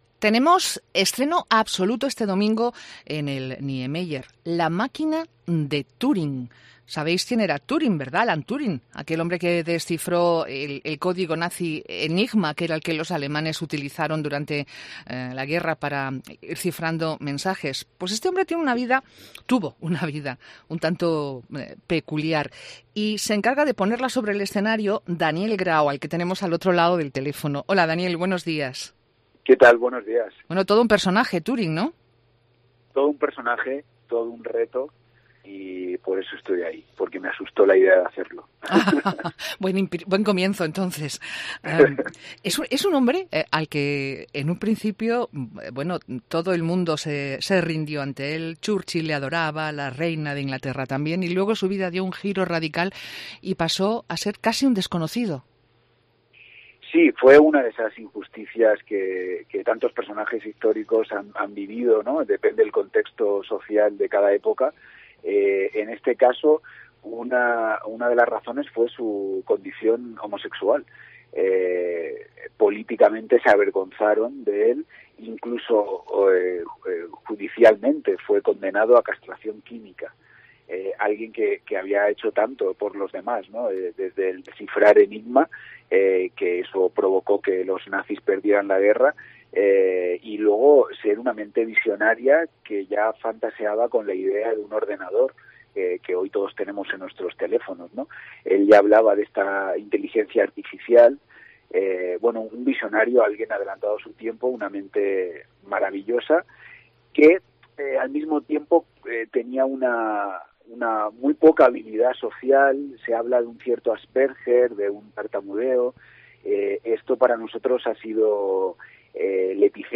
Entrevista con Daniel Grao